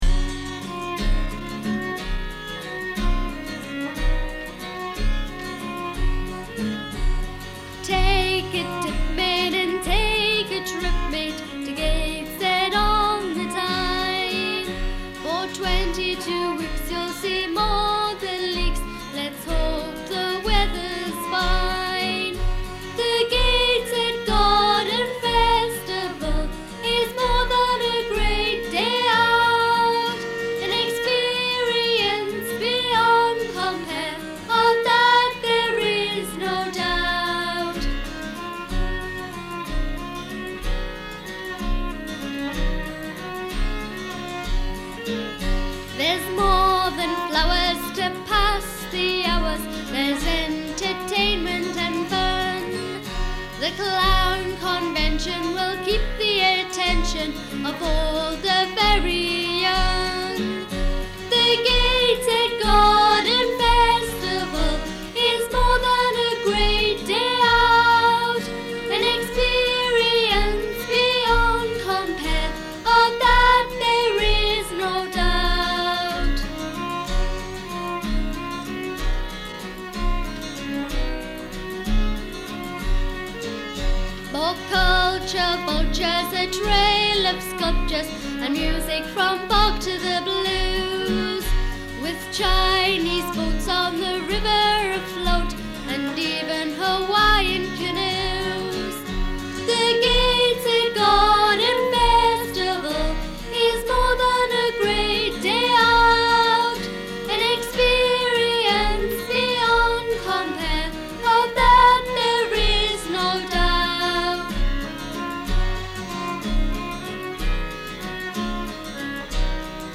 Vocals, Guitar & Mandolin
Fiddle, Northumbrian Pipes & Penny Whistle
Vocals & Flute.